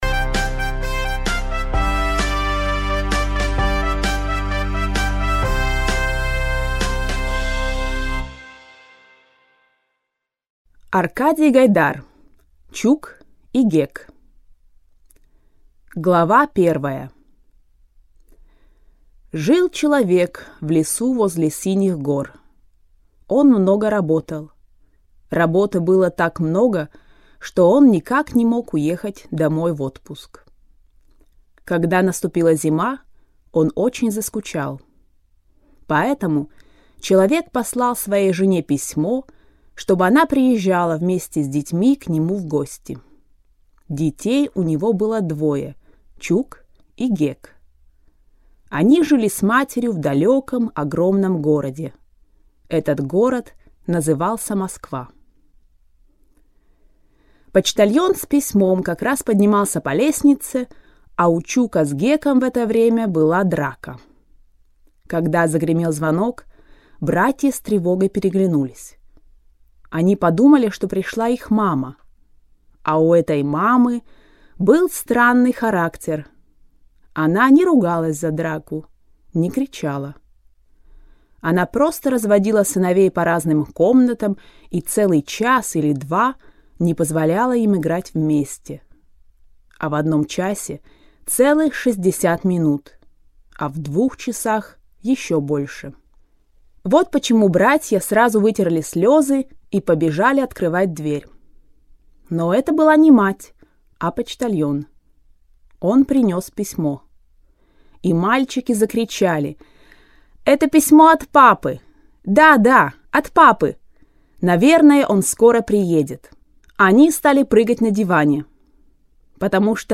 Audio kniha
Ukázka z knihy
Dojemný příběh o cestě malých chlapců a jejich maminky za tatínkem, který pracuje daleko na severu, je protkán neuvěřitelně světlou a dobrou atmosférou.Autor knihy, Arkadij Gajdar, s láskou píše o malých šibalech a neposedech a podrobně popisuje jejich malé pranice a velká dobrodružství, jež zažívají po cestě.Audiokniha nabízí převyprávěný příběh v ruštině, který je zpracován pro začátečníky a mírně pokročile posluchače. Text namluvila rodilá mluvčí.